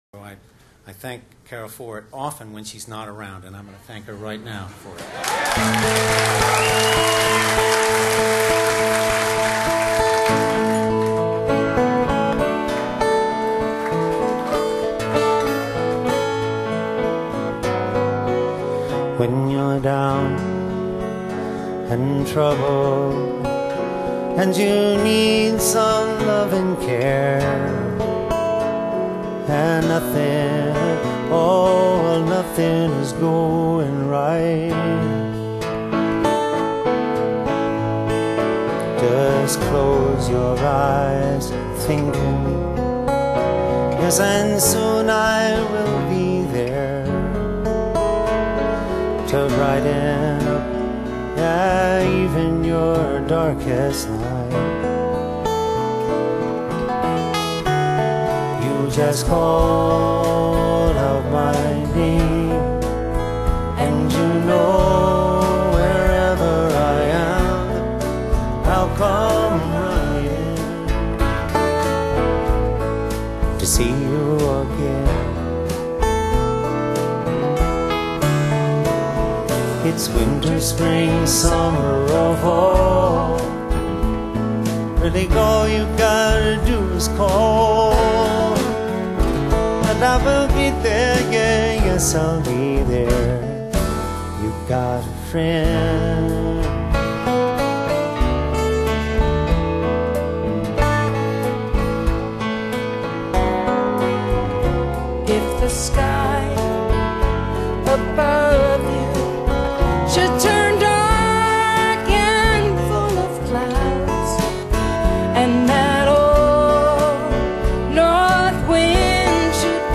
【民謠行吟詩人】